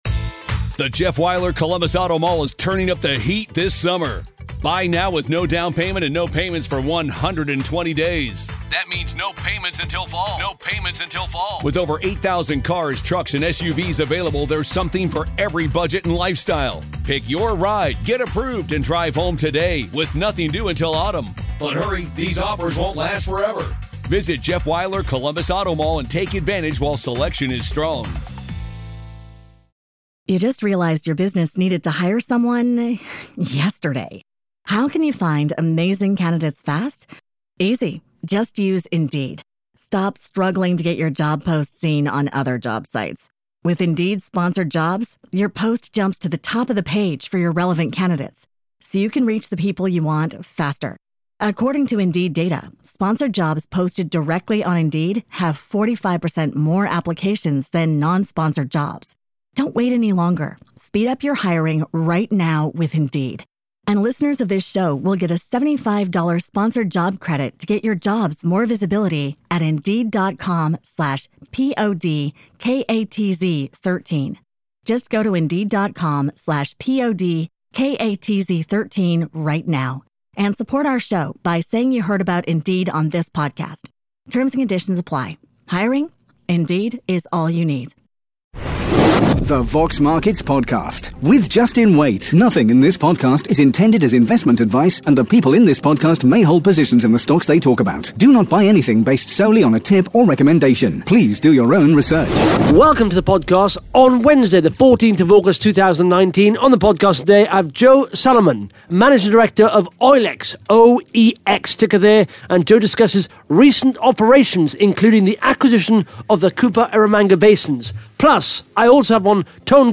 (Interview starts at 10 minutes 14 seconds) Plus the Top 5 Most Followed Companies & the Top 5 Most Liked RNS’s on Vox Markets in the last 24 hours.